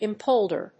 音節im・pol・der 発音記号・読み方
/ɪmpóʊldɚ(米国英語), ɪmpˈəʊldə(英国英語)/